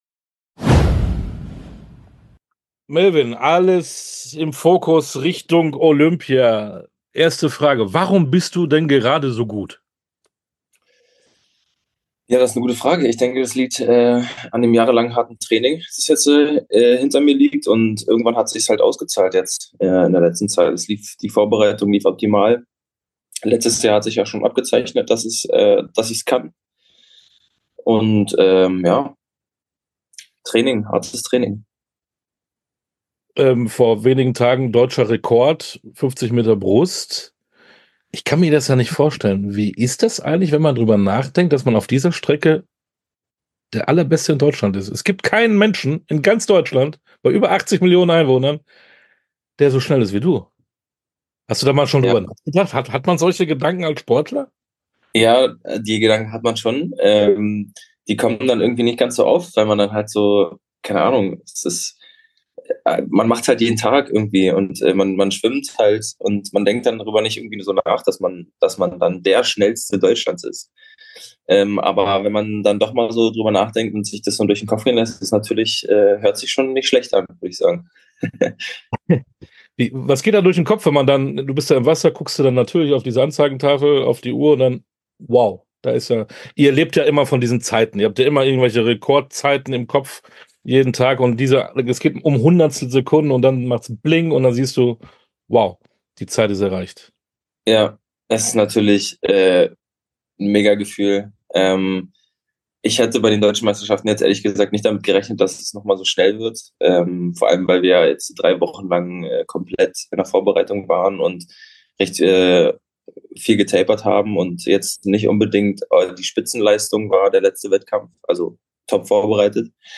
Sportstunde - Interview komplett Melvin Imoudu, Schwimmer ~ Sportstunde - Interviews in voller Länge Podcast